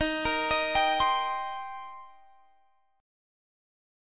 Listen to D#6 strummed